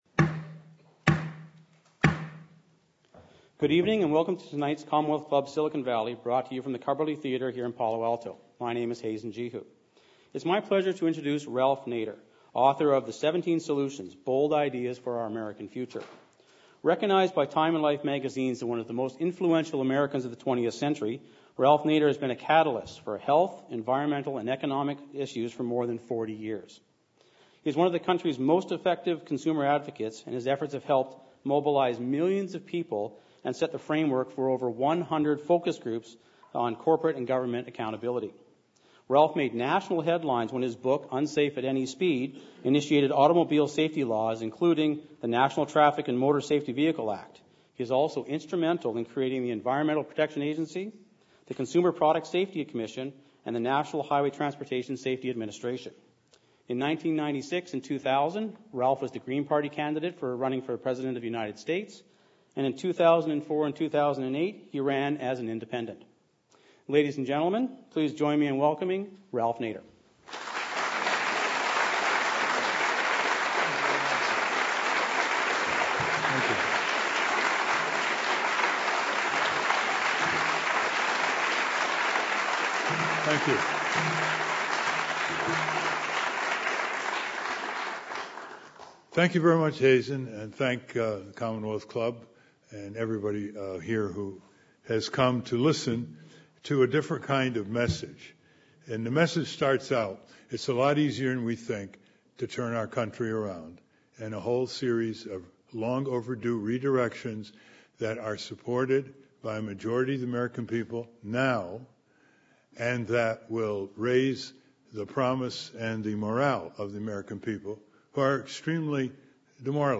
Former Presidential Candidate; Author, The Seventeen Solutions: Bold Ideas for Our American Future Judge LaDoris H. Cordell (ret), Independent Police Auditor, City of San Jose - Moderator Nader warns that our country is in the midst of serious fiscal and social distress.